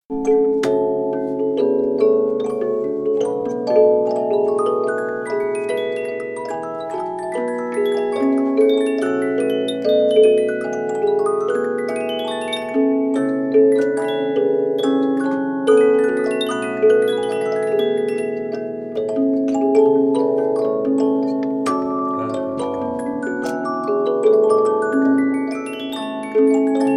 Musique d'une boîte à musique
Pièce musicale inédite